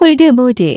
그래서 전문 성우의 목소리로 문장을 읽어주는 TTS 소프트웨어를 찾아봤는데, 마침 250자까지는 공짜(?)로 되는 착한 곳이 있더군요~
따라서 위의 TTS 프로그램으로 소리를 내면서 동시에 GoldWave로 녹음을 하면 되는 것입니다.